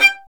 Index of /90_sSampleCDs/Roland L-CD702/VOL-1/STR_Violin 1 vb/STR_Vln1 % marc
STR VLN JE1O.wav